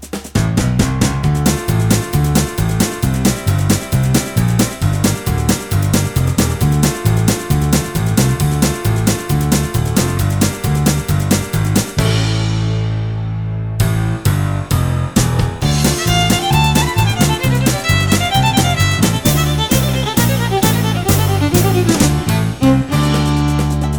no Backing Vocals Country (Male) 3:35 Buy £1.50